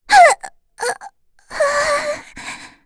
Frey-Vox_Dead.wav